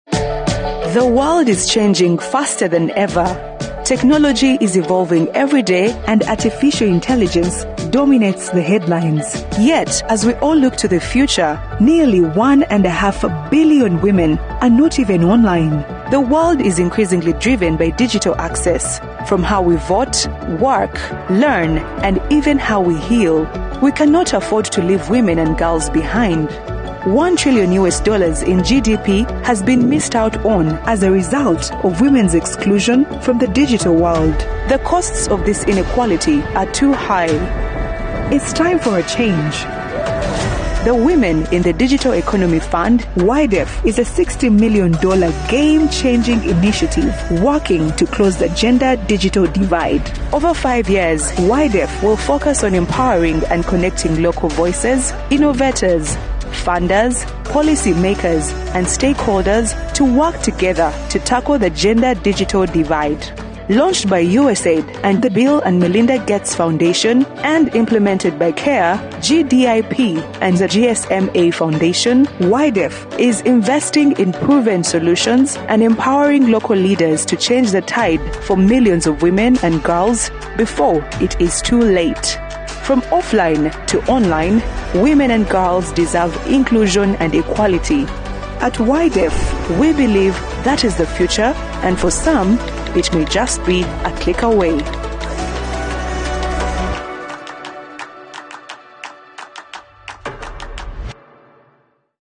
English (African)
Narration
PreSonus AudioBox 96 Studio Bundle
Young Adult
Middle-Aged